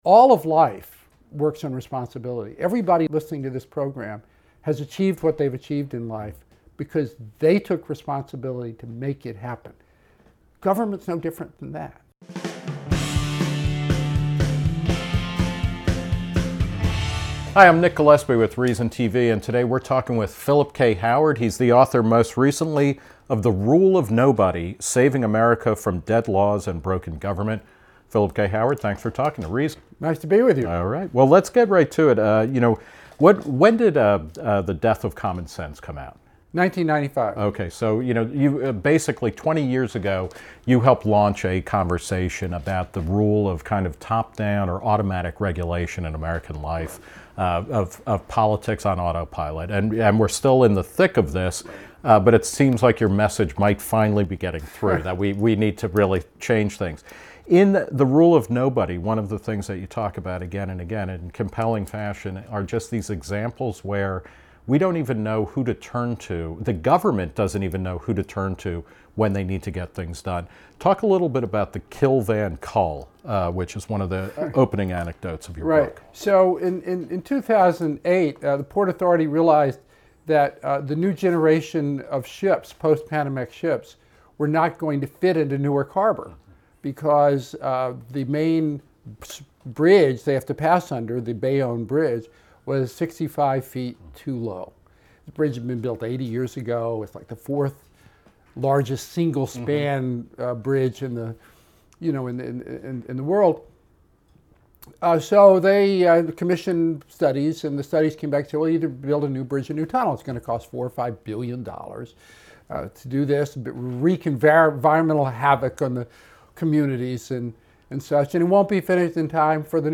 The Rule of Nobody: Saving America from Dead Laws and Broken Government - Q and A with Philip K. Howard